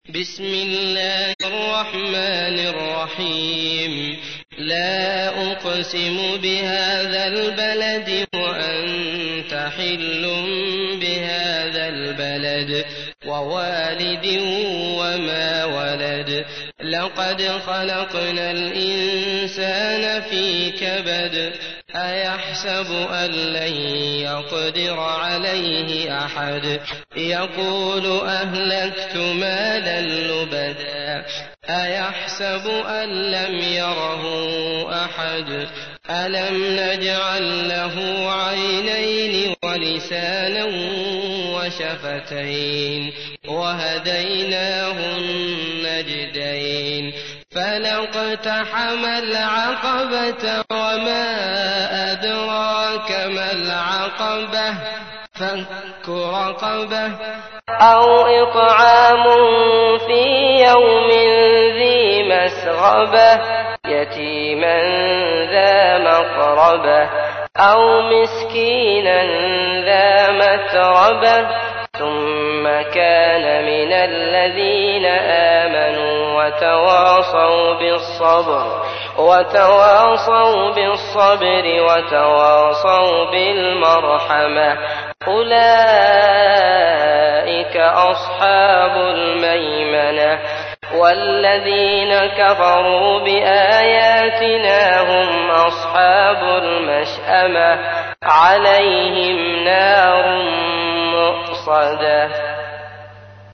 تحميل : 90. سورة البلد / القارئ عبد الله المطرود / القرآن الكريم / موقع يا حسين